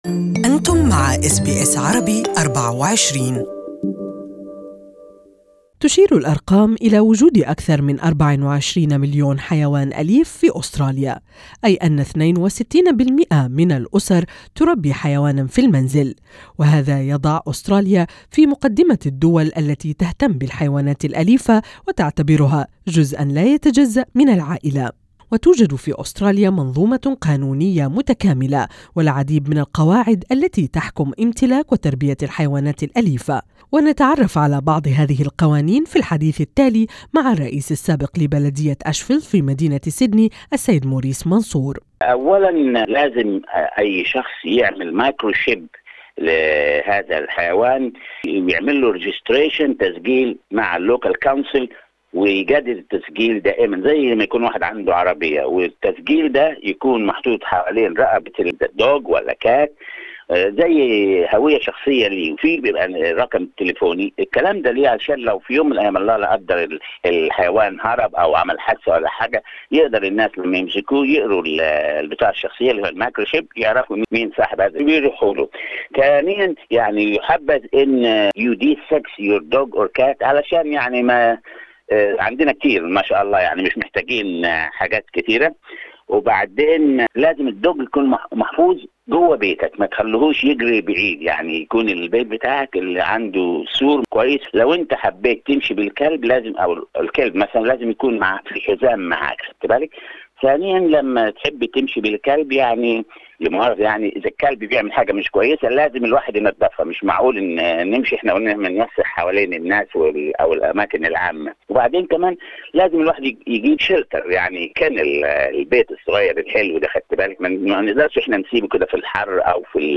We will learn about some of them in this interview with former mayor of Ashfield, Mr. Morris Mansour.